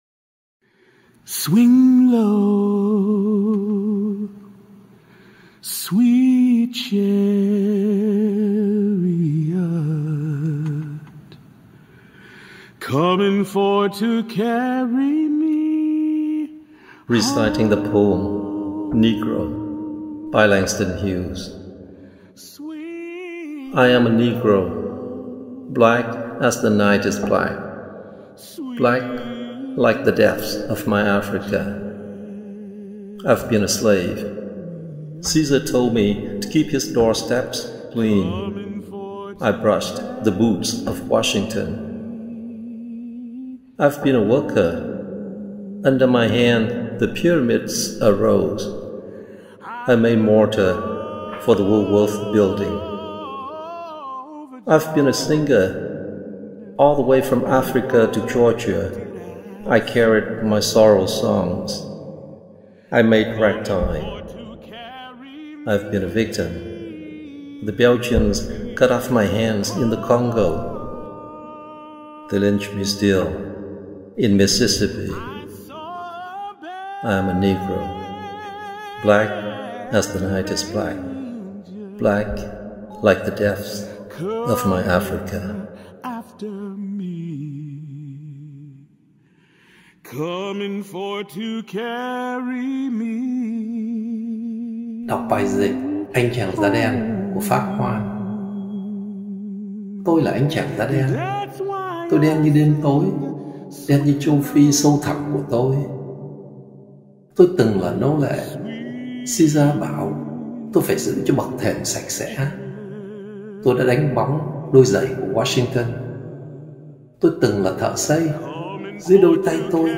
Poetry Reading: Anh Chàng Da Đen (Negro – Langston Hughes)